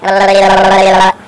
Dazzled Sound Effect
dazzled.mp3